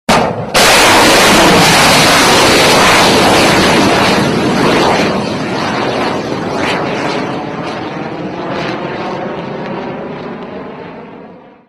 Запуск межконтинентальной ракеты (Missile launch)
Отличного качества, без посторонних шумов.
115_missile.mp3